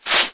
Hoe.wav